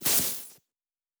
pgs/Assets/Audio/Sci-Fi Sounds/Electric/Spark 11.wav at 7452e70b8c5ad2f7daae623e1a952eb18c9caab4
Spark 11.wav